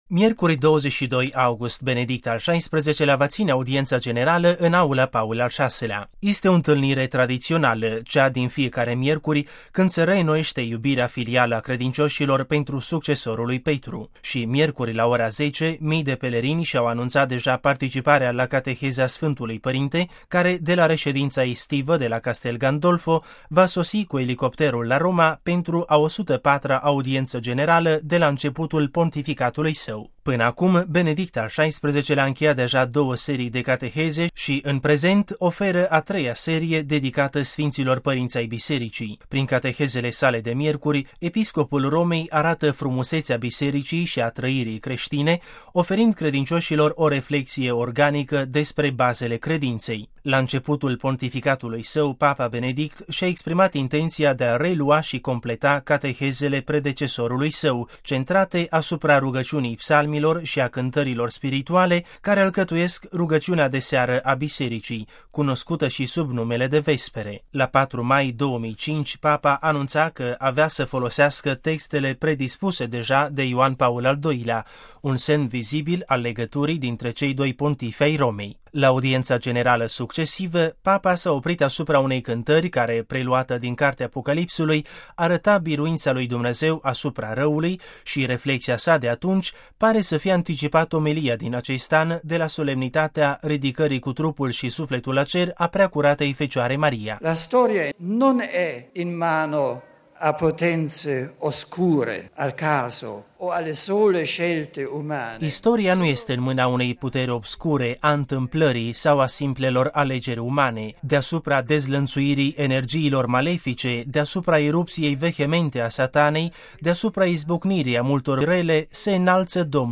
Aici, serviciul audio cu vocea Sfântului Părinte: RealAudio